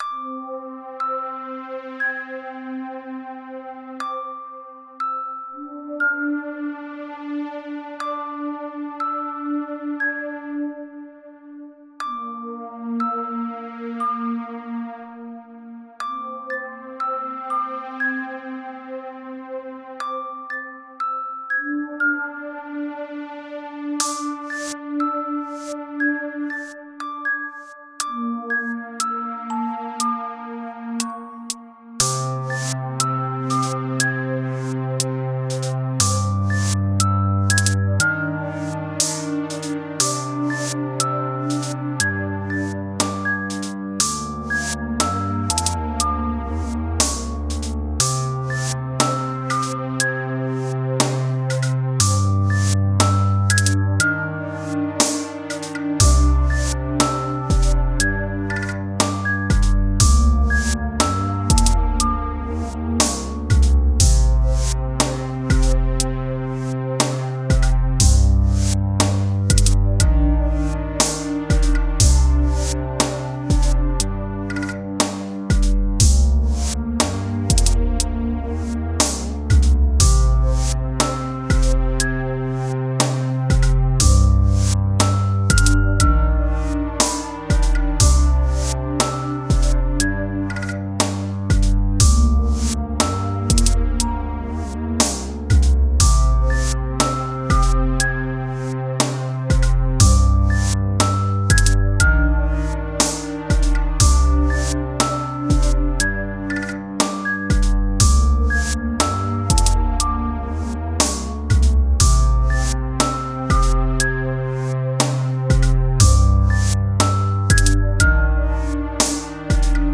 Going back to the creepy music box samples I found – I created this beat with quite an eerie vibe overall.